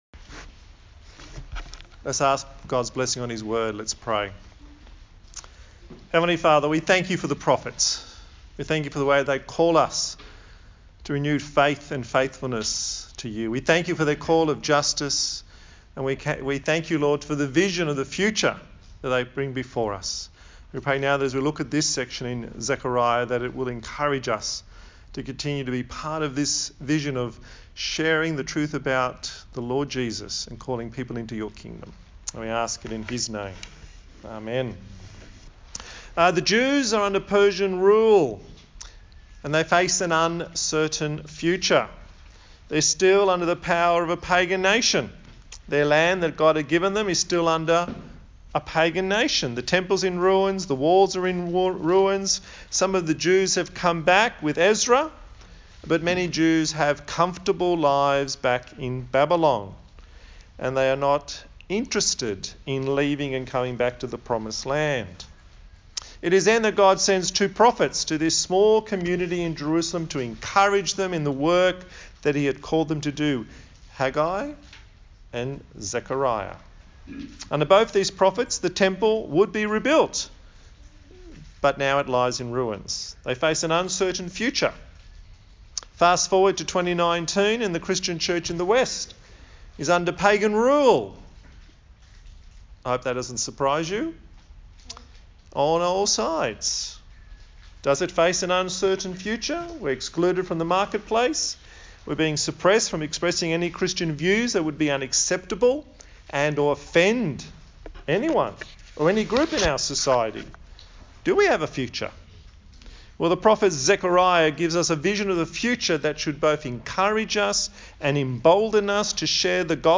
A sermon on the book of Zechariah